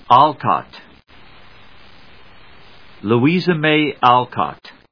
音節Al・cott 発音記号・読み方
/ˈɔːlkət(米国英語), ˈæˌlkɑ:t(英国英語)/